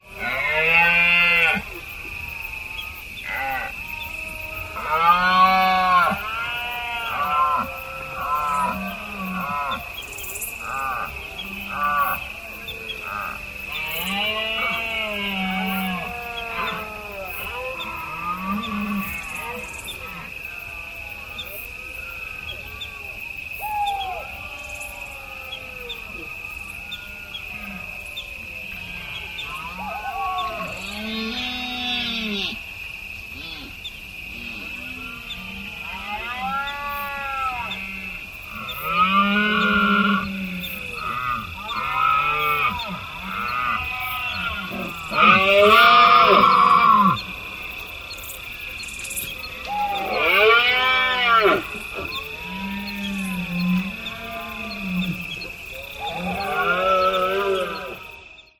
Los bramidos de los ciervos